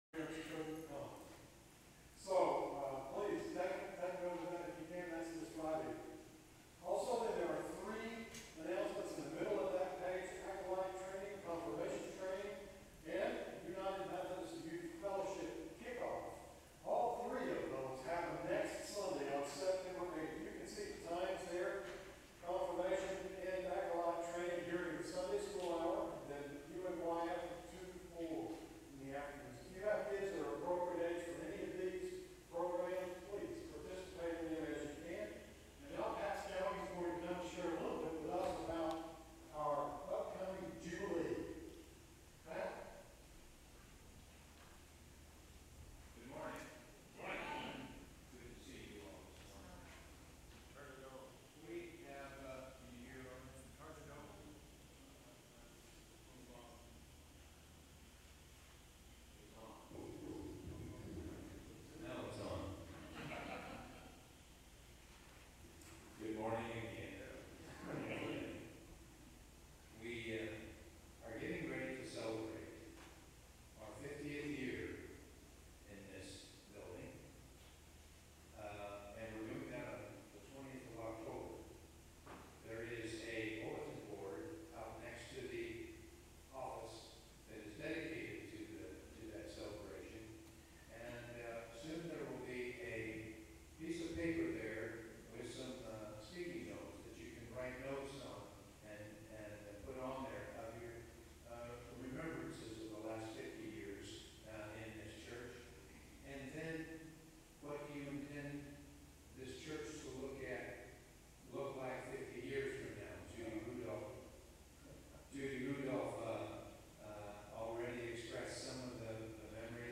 September 1, 2019 Service
Traditional Sermon